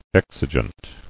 [ex·i·gent]